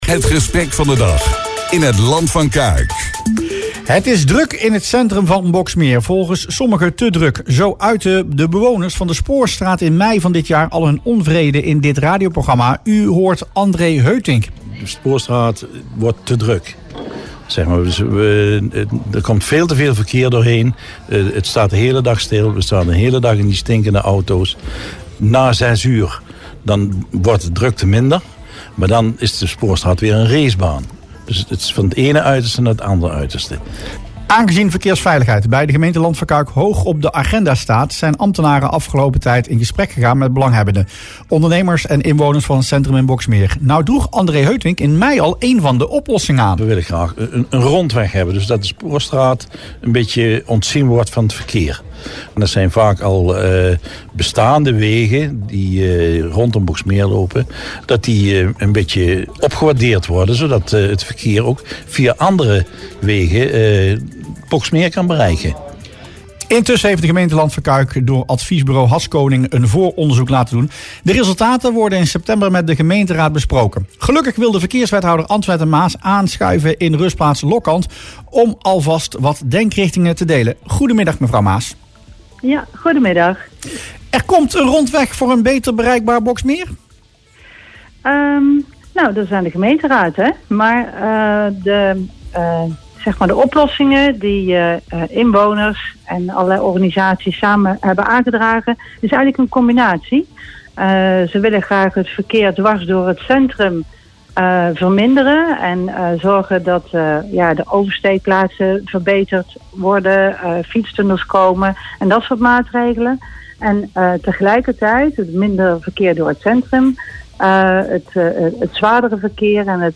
Dat is volgens verkeerswethouder Antoinette Maas een van de mogelijkheden om verkeersdrukte in en rond het centrum van Boxmeer te verminderen. Ze zei dat in het radioprogramma Rustplaats Lokkant.
Verkeerswethouder Antoinette Maas in Rustplaats Lokkant